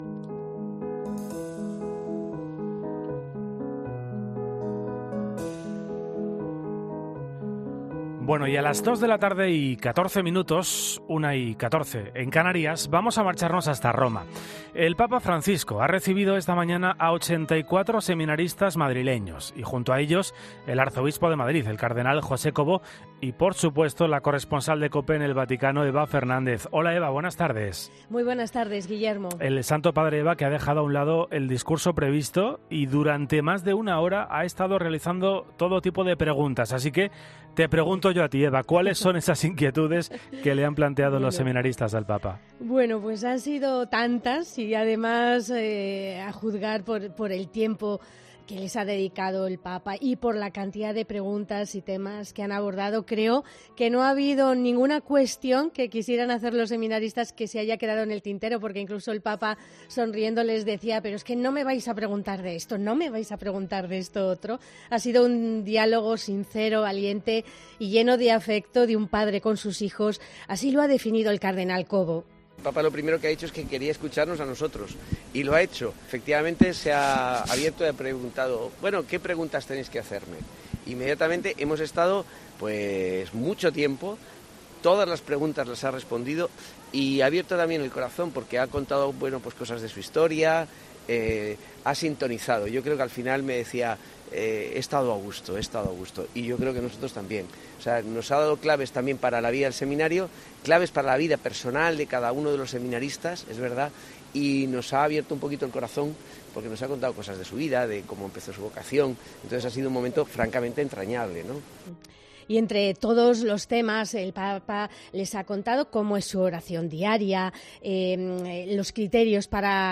De hecho, el propio Arzobispo de Madrid, José Cobo, decía ante los micrófonos de COPE que, lo primero que ha dicho el Papa, ha sido que "quería escucharnos a nosotros. Se ha abierto a nosotros y nos ha preguntado por las preguntas".
La voz de los seminaristas